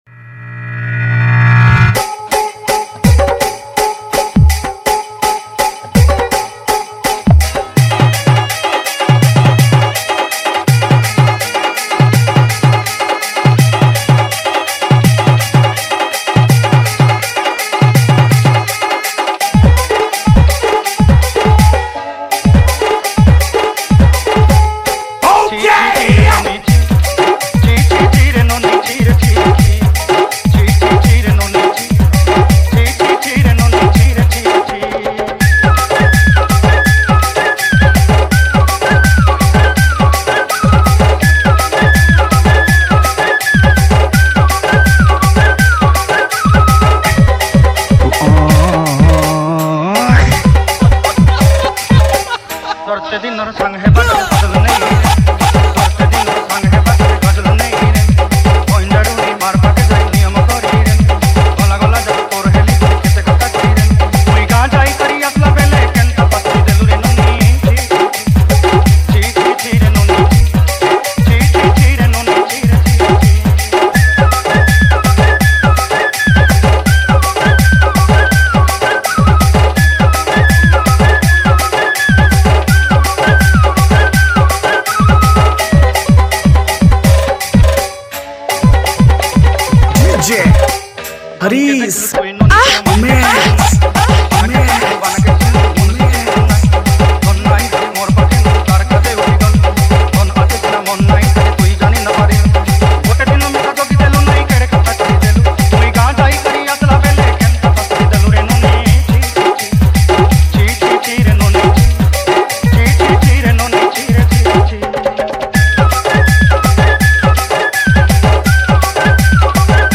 • Category: New Sambalpuri DJ Song 2025